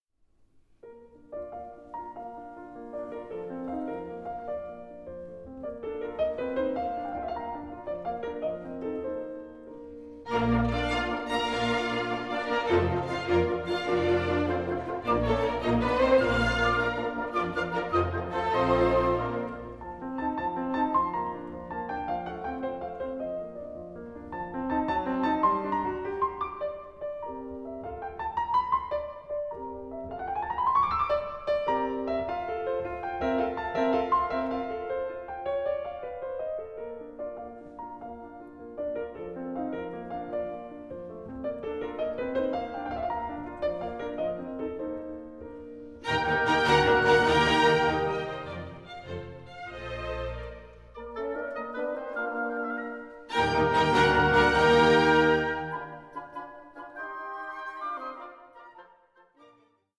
Rondo. Allegro 9.31